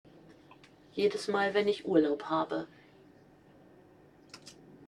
Standort der Erzählbox:
MS Wissenschaft @ Diverse Häfen
Standort war das Wechselnde Häfen in Deutschland.